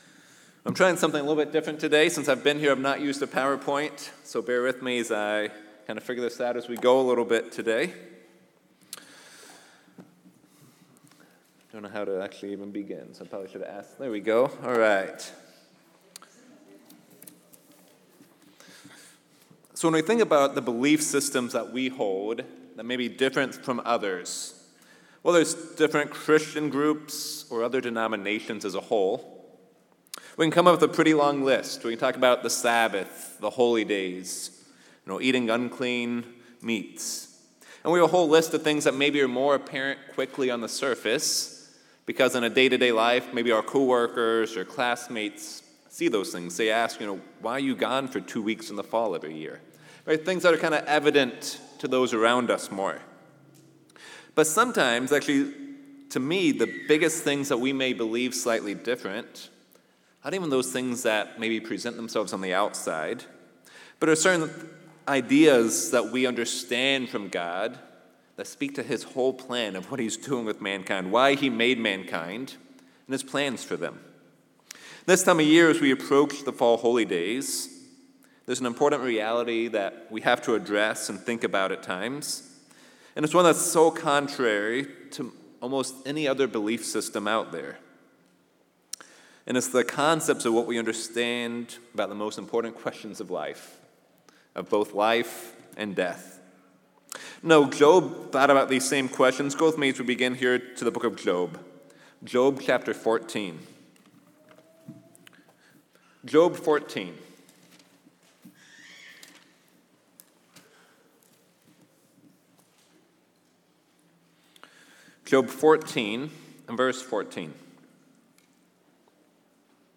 In this message, we are going to discuss what happens at life and death. Part two of this sermon was given on November 2, 2024.
Given in Columbus, OH